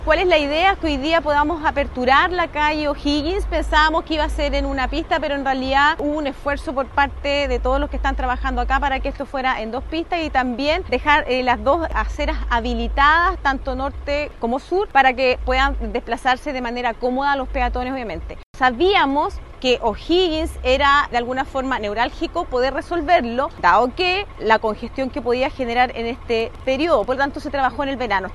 Por lo mismo, la seremi de Vivienda, Claudia Toledo, explicó que fueron habilitadas ambas pistas de la calzada y no solo una, como se había proyectado.